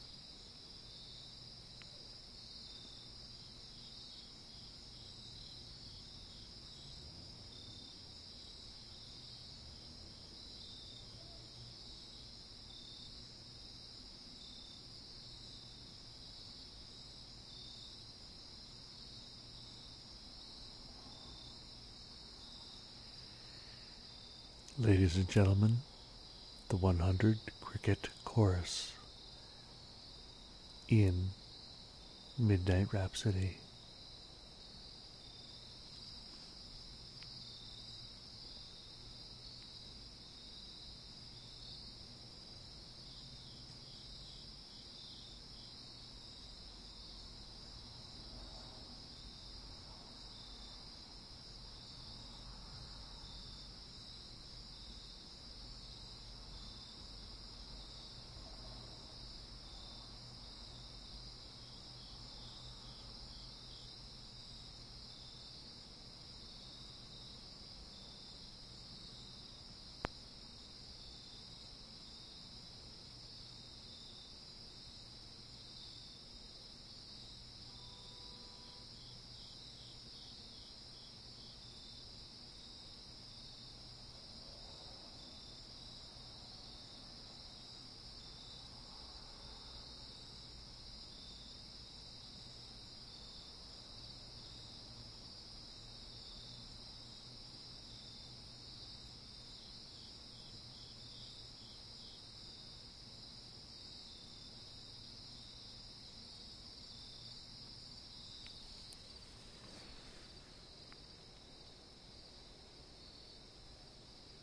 Crickets on a late sumner night
60733-crickets-on-a-late-sumner-night.mp3